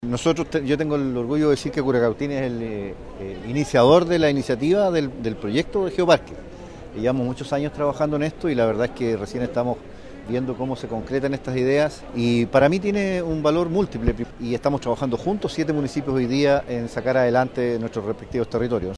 Con delegaciones de más de 50 países se inauguró en el Teatro Municipal de Temuco, región de La Araucanía, la undécima conferencia internacional de Geoparques de la Unesco, que se realiza por primera vez en Sudamérica.
A su vez, el alcalde de Curacautín, Hugo Vidal, hizo foco en el valor de un trabajo coordinado entre municipios.